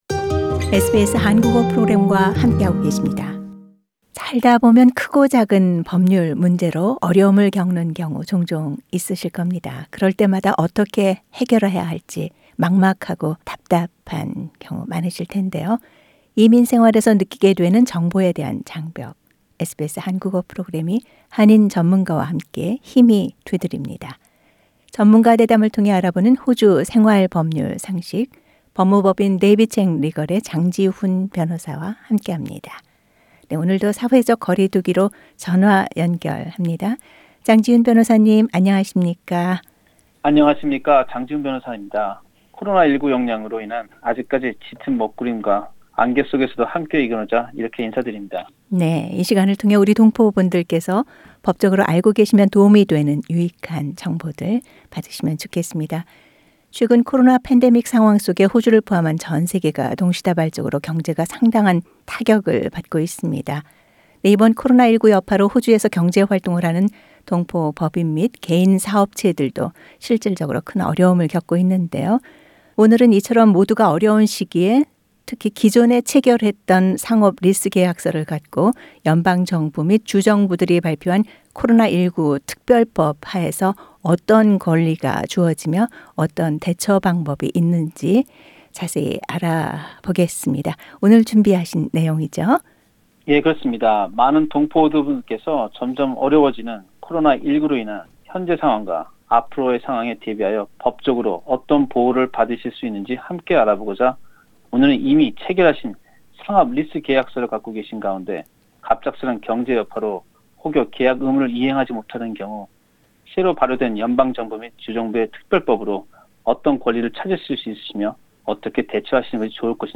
오늘도 사회적 거리두기로 전화 연결합니다.